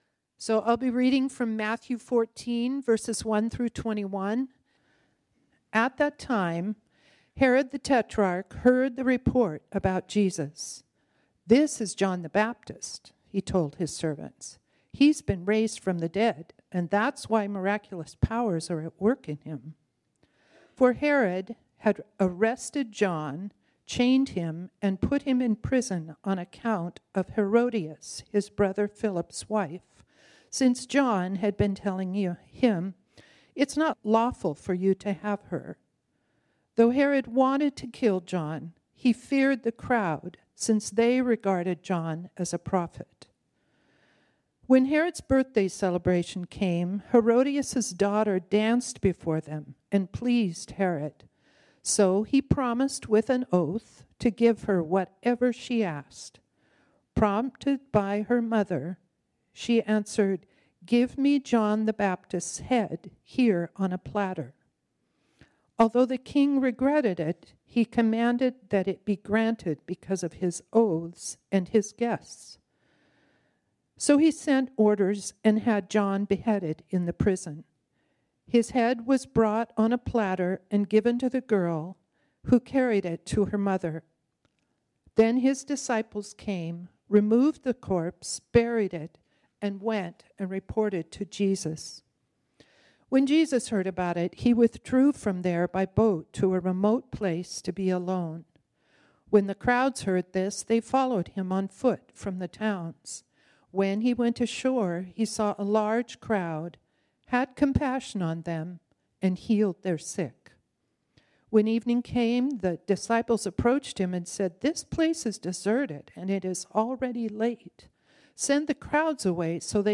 This sermon was originally preached on Sunday, June 23, 2024.